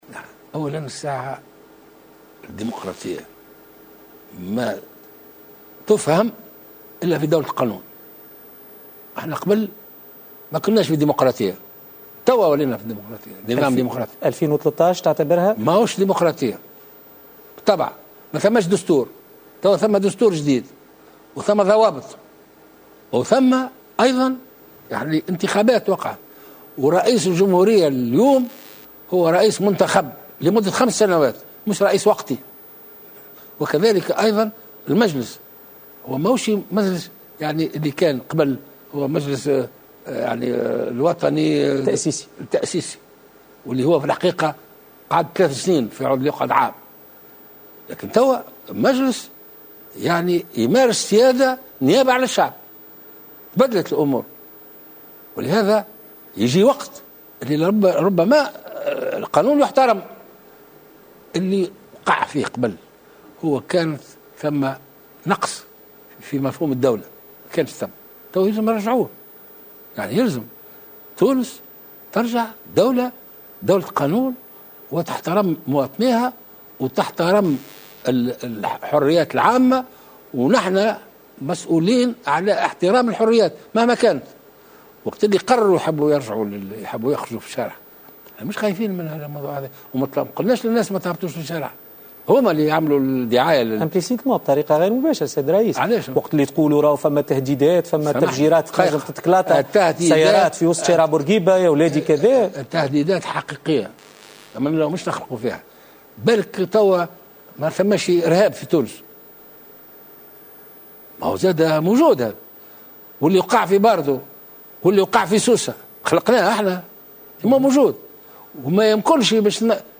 Le président de la République, Beji Caïed Essebsi, a déclaré ce mardi 22 septembre 2015 dans une interview accordée à la chaine télévisée Nessma TV, que lors du règne de la Troïka, il n’y avait pas de démocratie.